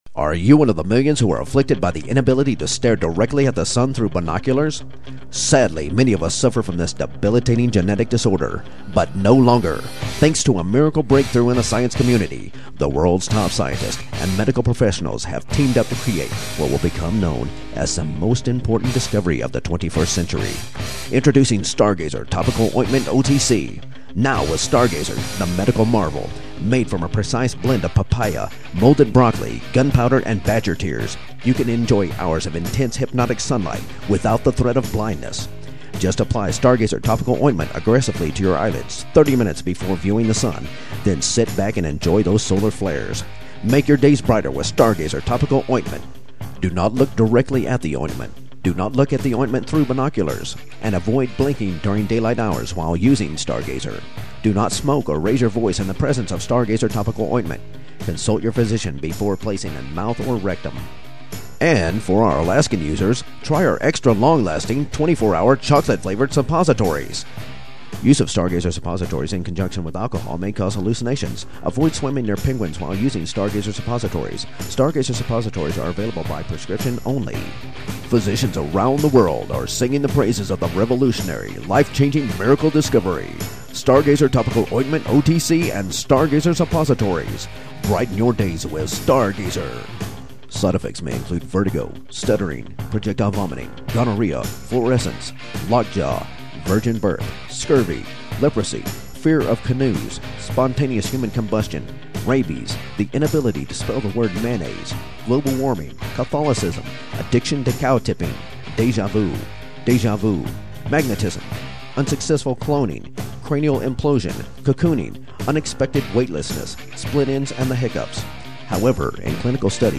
And now a word from our sponsor,